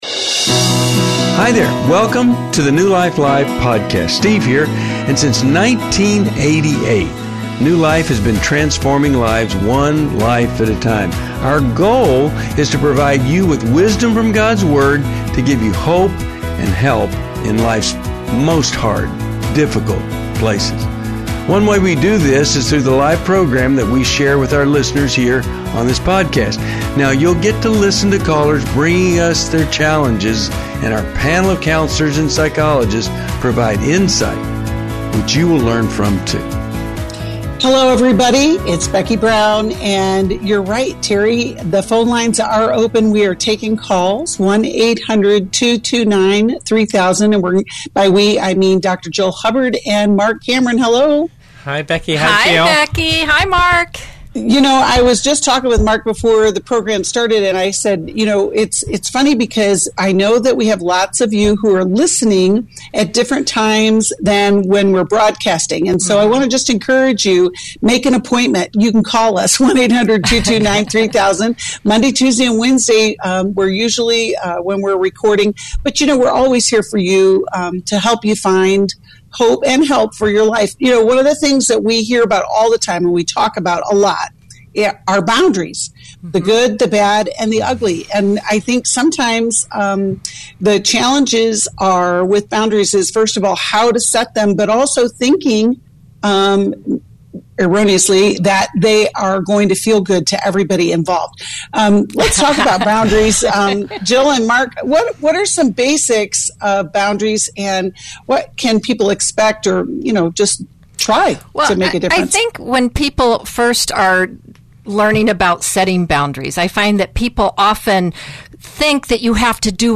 New Life Live: April 25, 2023 - Explore marriage challenges, social media concerns, and family dynamics as callers seek guidance on respect and integrity.